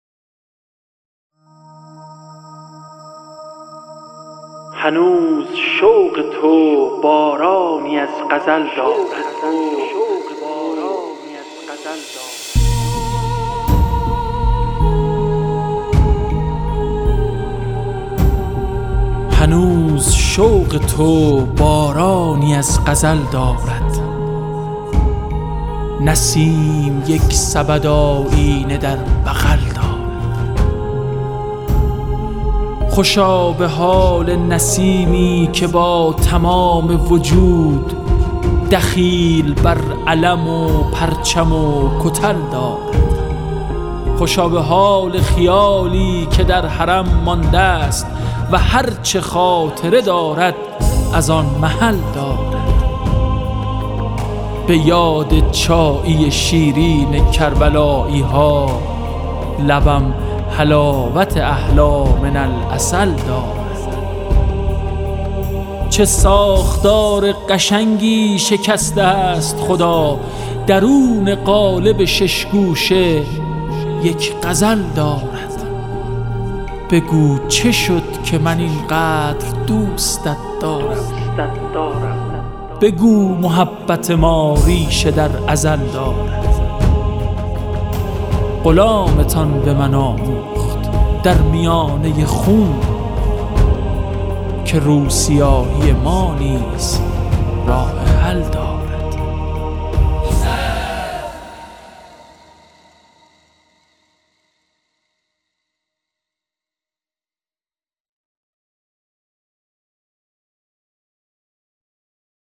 شعرخوانی حمیدرضا برقعی